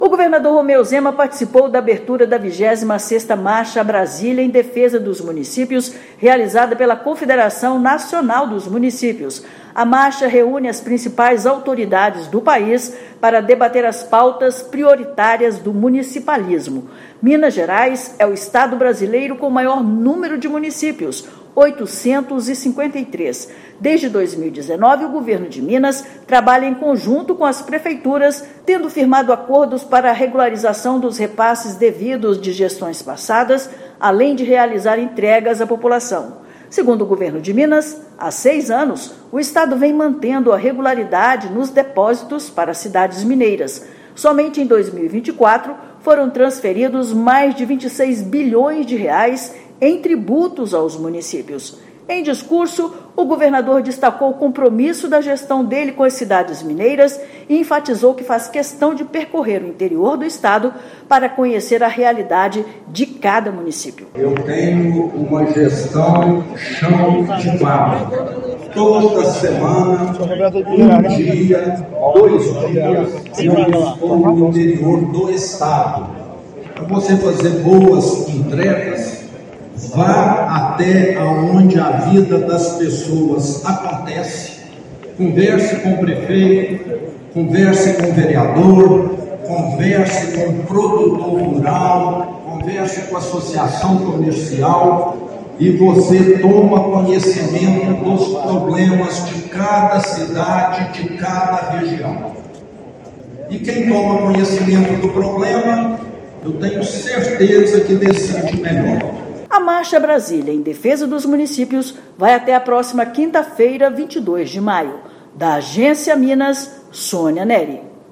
Evento reúne gestores municipais e autoridades de todo o país para discutir os desafios da vida nas cidades. Ouça matéria de rádio.